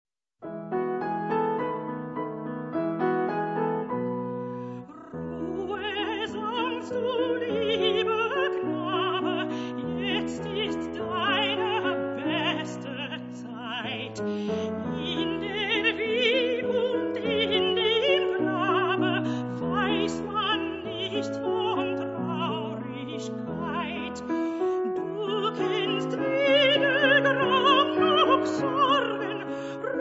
mezzo soprano